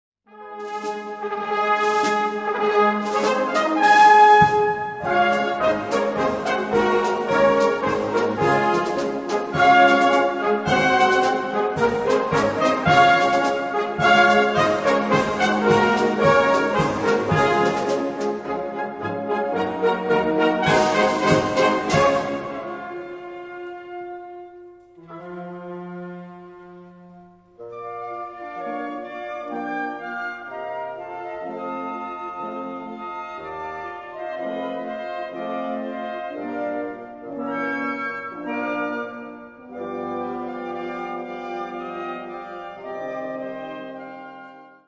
Gattung: Walzer
A4 Besetzung: Blasorchester Zu hören auf